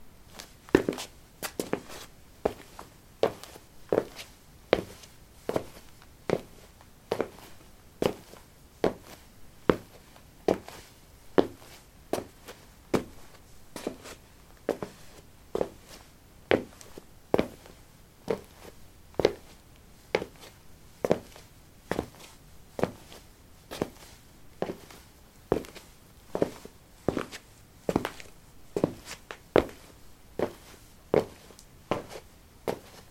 脚步声 油毡 " lino 12a squeakysportshoes walk
描述：在油毡上行走：吱吱作响的运动鞋。在房子的地下室用ZOOM H2记录，用Audacity标准化。
Tag: 脚步 步骤 步骤 走路 脚步 散步